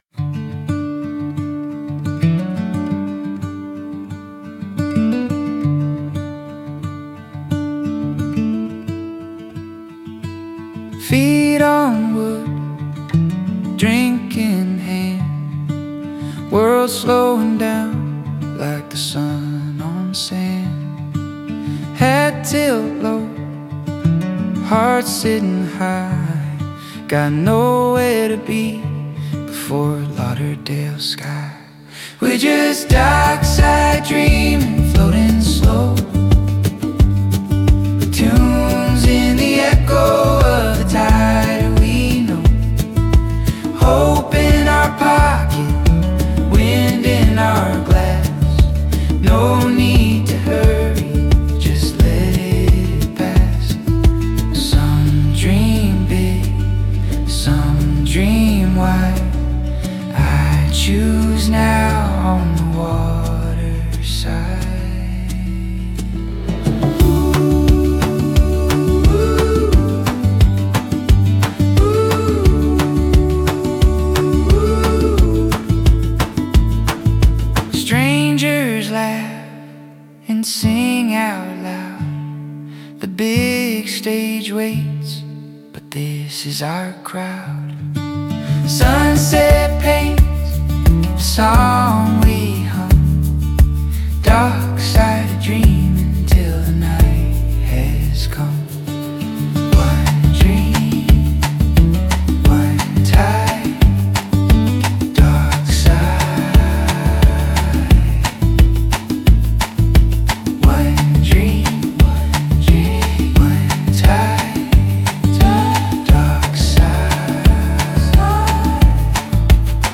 Chill Blues